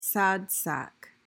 PRONUNCIATION: (SAD sak) MEANING: noun: A well-meaning but hopelessly inept person.